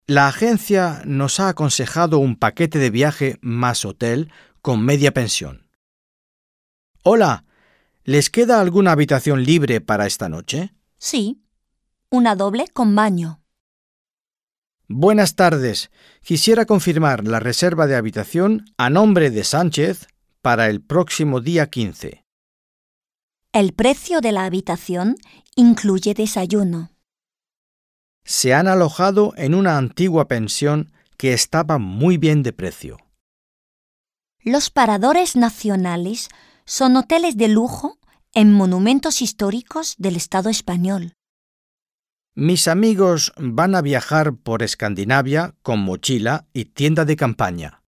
Un peu de conversation - Le logement